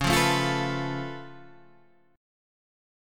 C# Augmented 7th